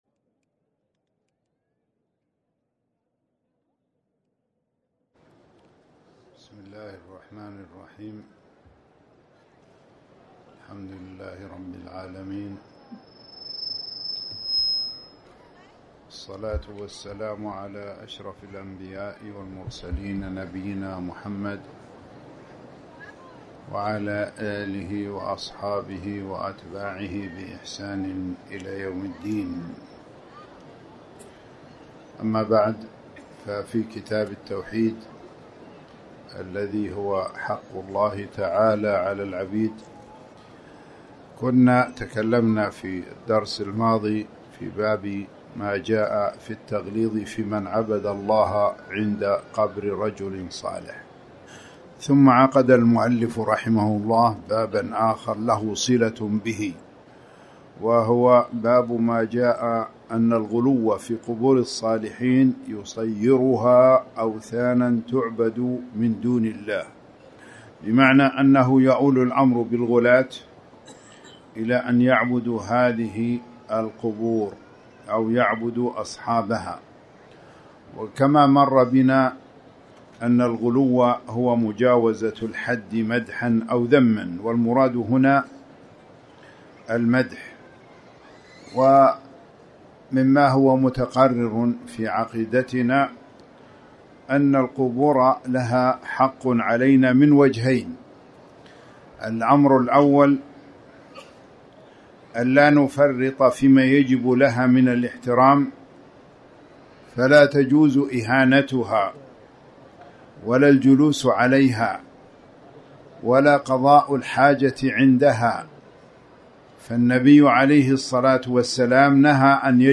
تاريخ النشر ١٩ صفر ١٤٤٠ هـ المكان: المسجد الحرام الشيخ